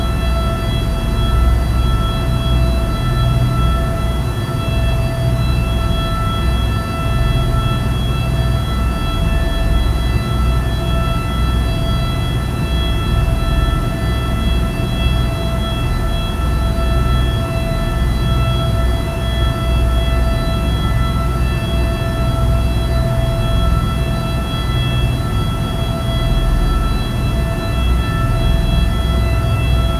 EC130_In_Turbine-left.wav